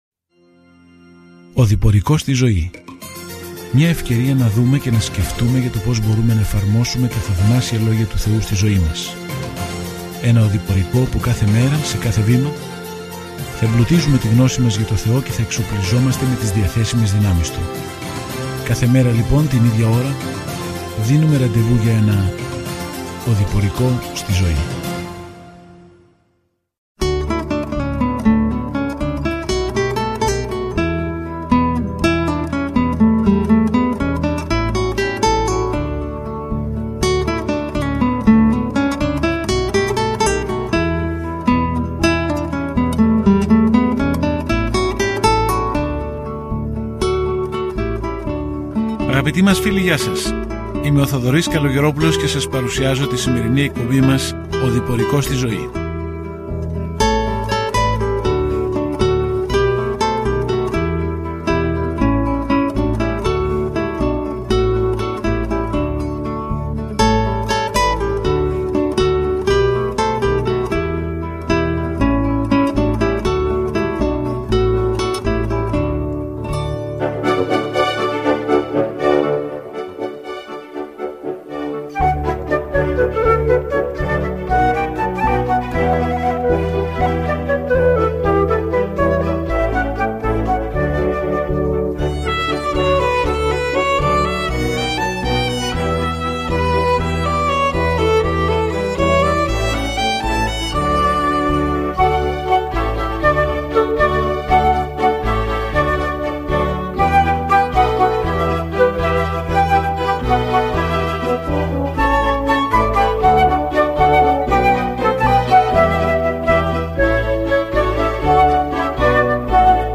Κείμενο ΚΑΤΑ ΜΑΡΚΟΝ 9:14-50 Ημέρα 15 Έναρξη αυτού του σχεδίου Ημέρα 17 Σχετικά με αυτό το σχέδιο Το συντομότερο Ευαγγέλιο του Μάρκου περιγράφει την επίγεια διακονία του Ιησού Χριστού ως τον πάσχοντα Υπηρέτη και Υιό του Ανθρώπου. Καθημερινά ταξιδεύετε στον Μάρκο καθώς ακούτε την ηχητική μελέτη και διαβάζετε επιλεγμένους στίχους από τον λόγο του Θεού.